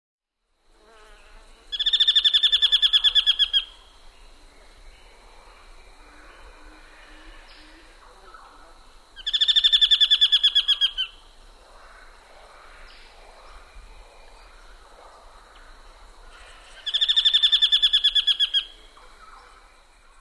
8. Forest Kingfisher
ForestKingfisher.mp3